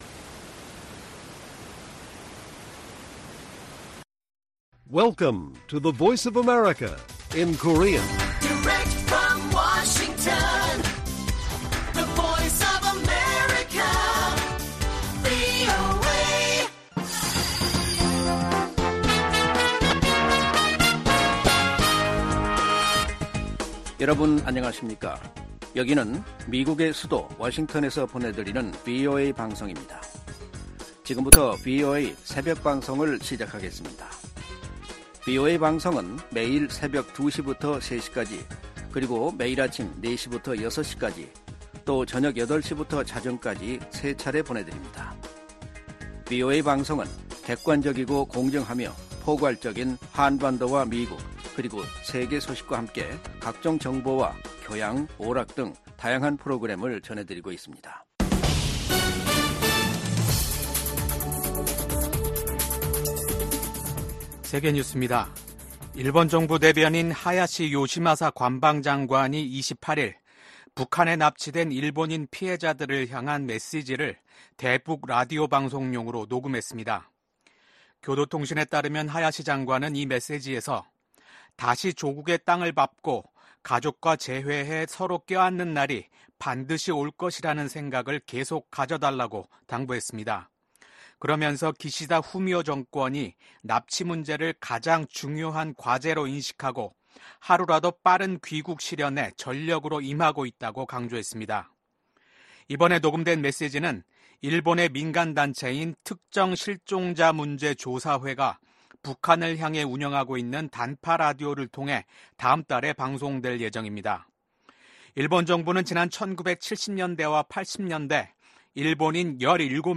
VOA 한국어 '출발 뉴스 쇼', 2024년 5월 29일 방송입니다. 북한이 27일 밤 ‘군사 정찰위성’을 발사했지만 실패했습니다.